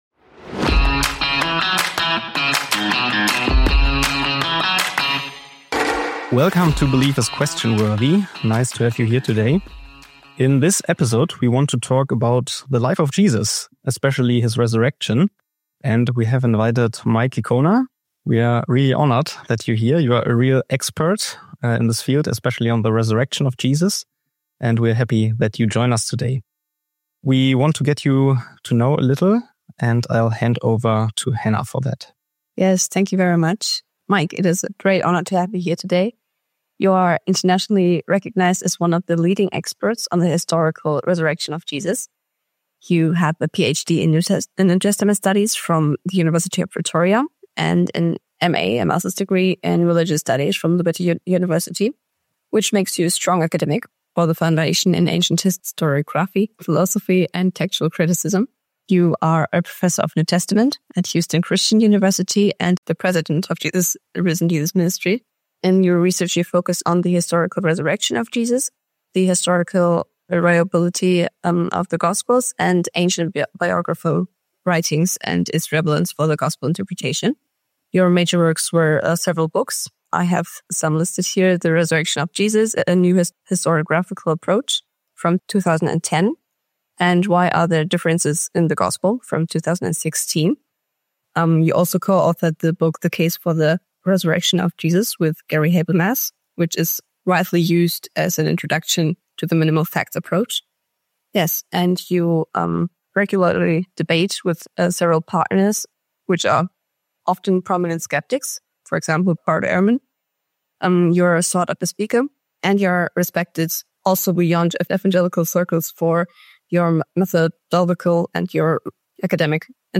Fact or Fiction? The Ressurection of Jesus - A Conversation